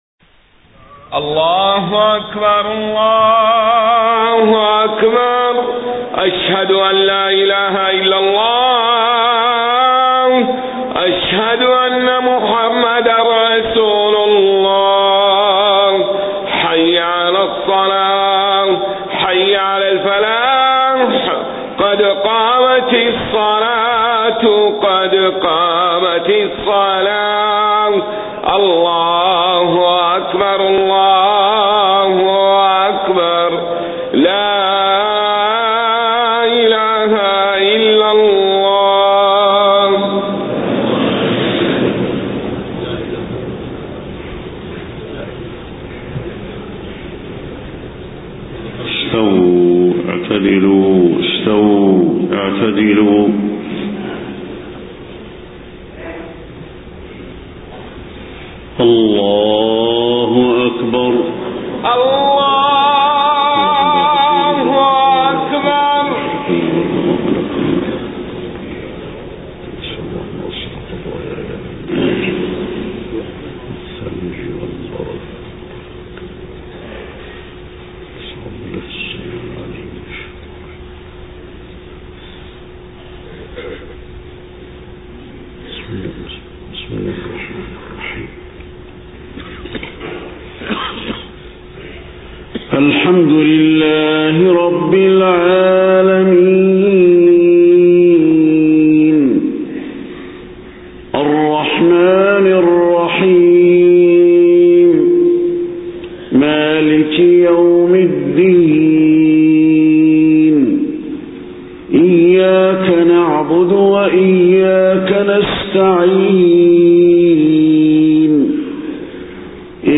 صلاة الفجر 13 ربيع الأول 1431هـ سورة الحاقة كاملة > 1431 🕌 > الفروض - تلاوات الحرمين